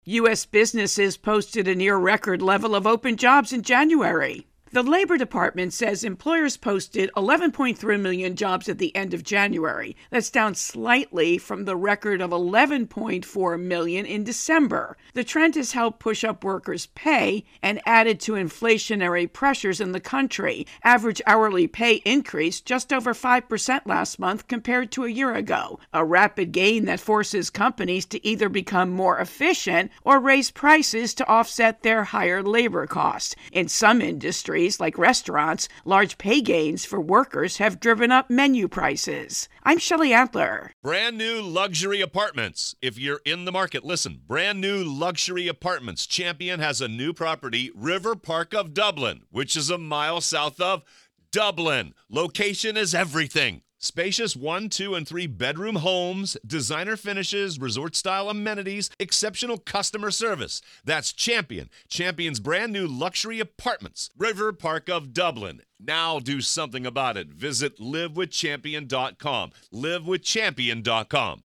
Jobs intro and voicer